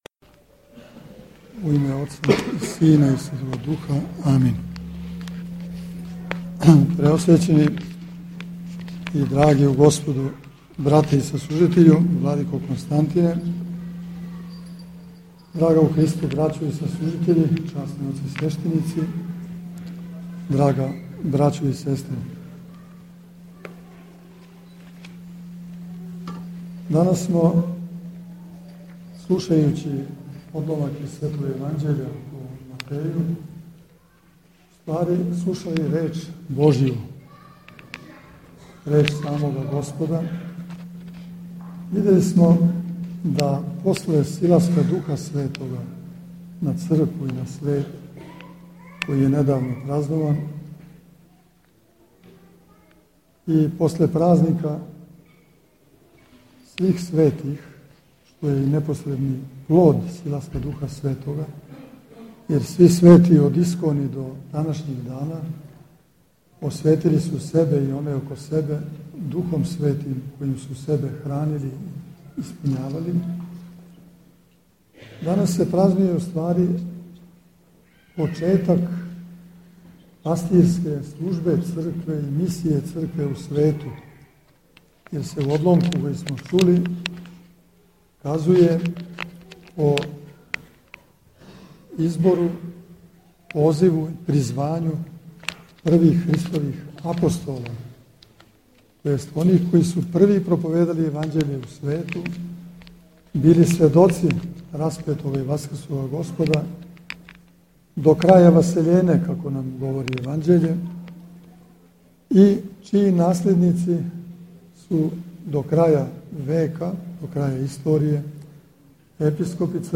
Епископ Иринеј је сабранима на светој Евхаристији пренео архипастирски поздрав Његове Светости Патријарха српског Господина Иринеја и Светог Архијерејског Синода Српске Православне Цркве.